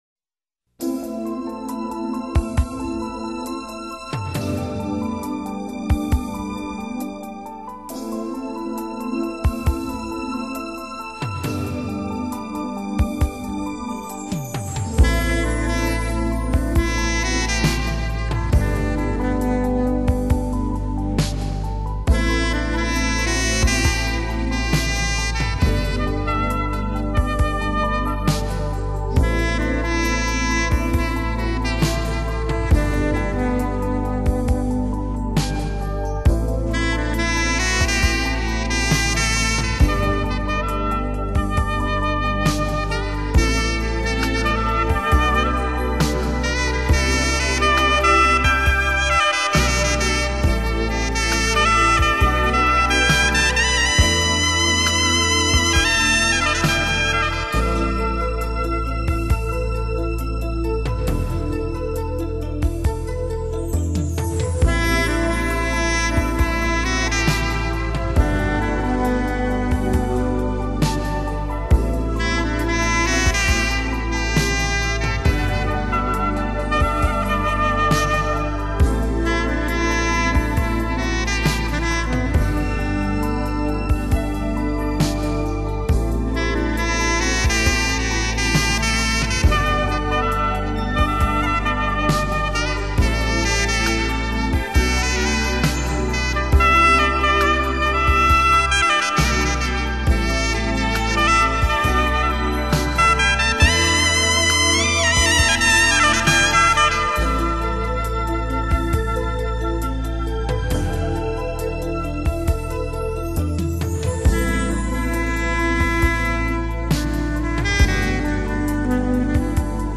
珍贵无比的管乐名曲录音